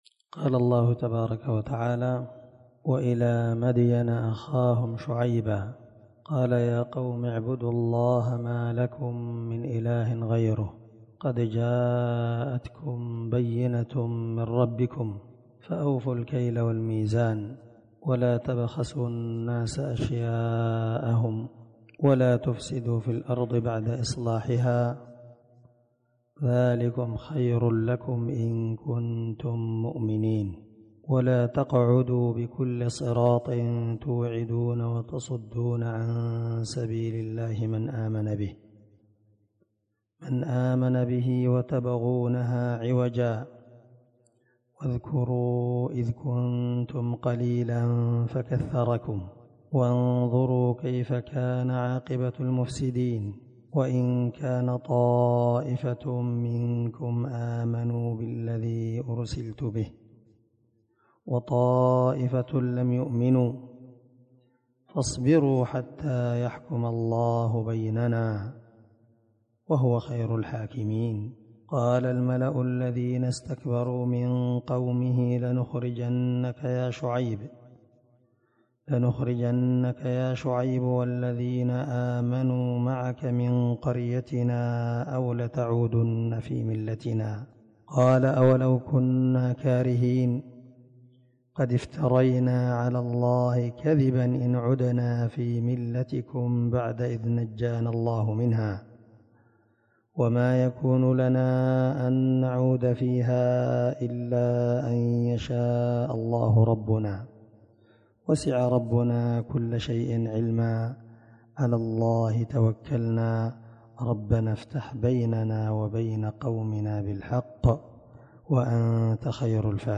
475الدرس 27 تفسير آية ( 85 – 93 ) من سورة الأعراف من تفسير القران الكريم مع قراءة لتفسير السعدي
دار الحديث- المَحاوِلة- الصبيحة.